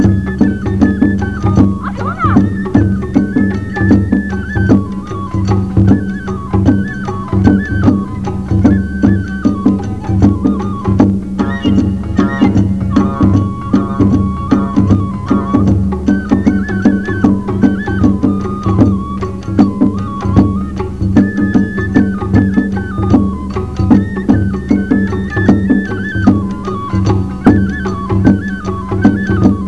下りやまは目的を終えたときの帰りの囃子になります。音色は「ぺれれ、ぺれれ」と軽い「てんぽ」で道中囃子とも言われ
囃子方は大太鼓、小太鼓、笛、摺金、三味線など使用して曳山には６人から８人 位乗っています。